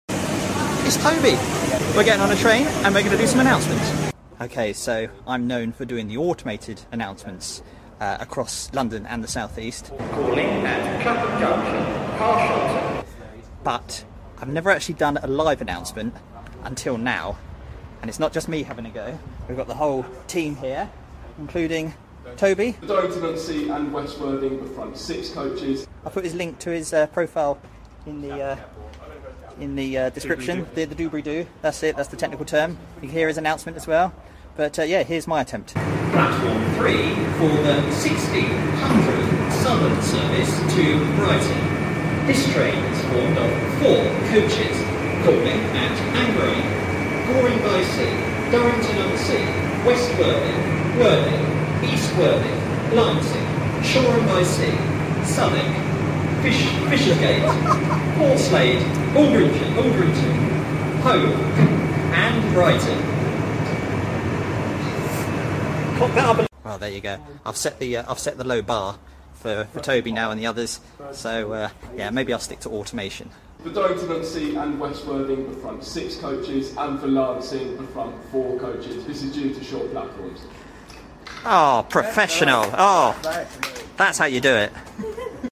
My first LIVE announcement rather sound effects free download